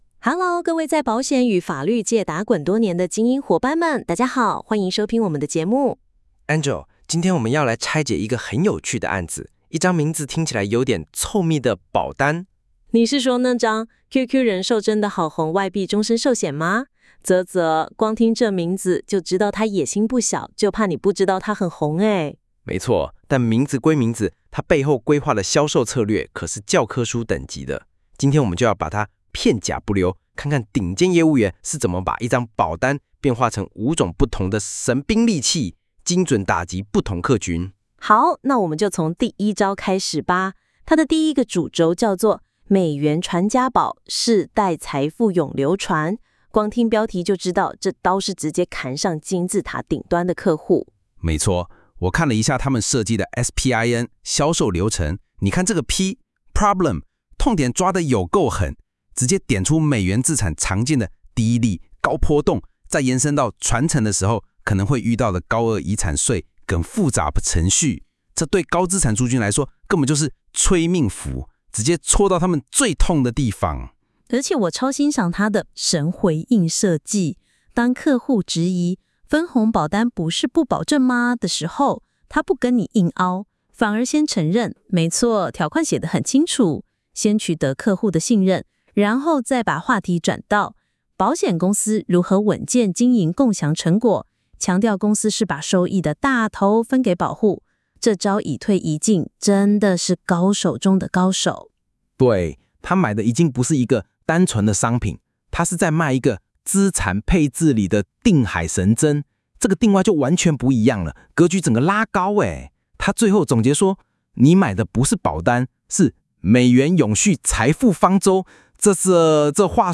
將業務手冊中的精華內容，以對談、故事或案例分享的形式錄製成音頻節目。